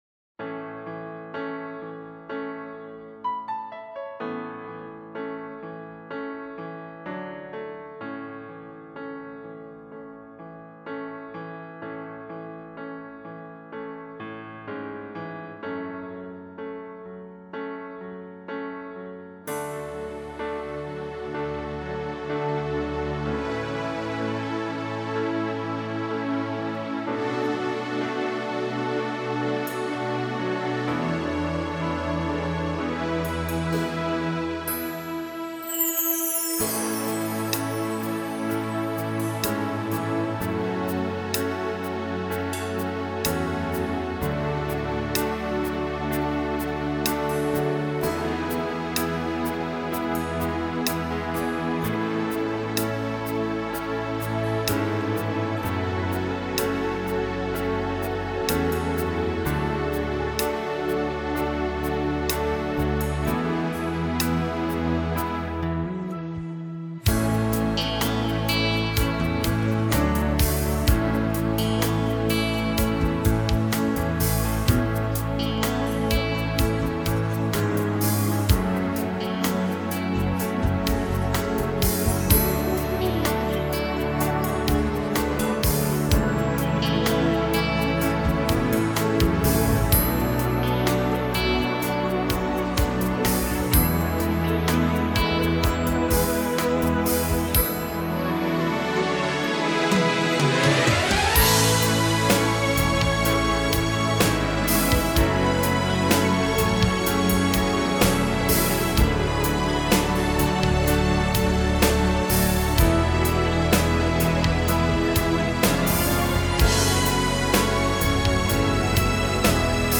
минусовка версия 33821